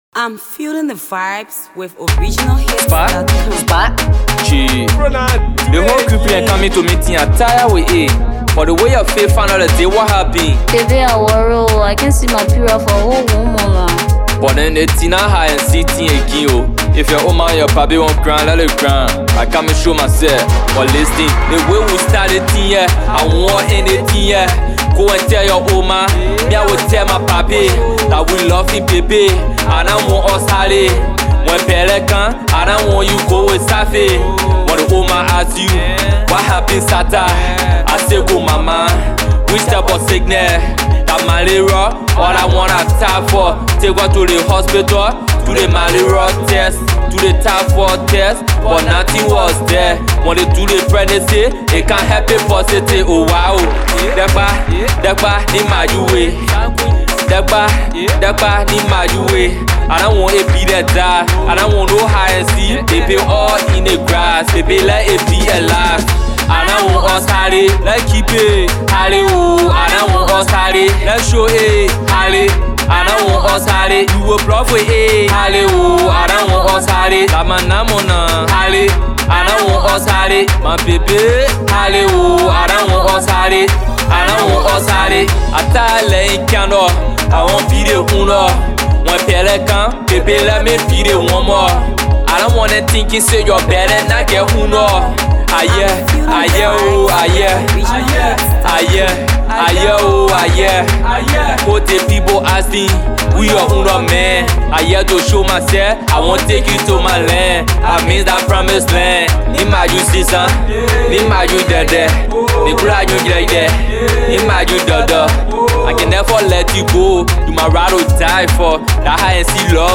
Talented Liberian artist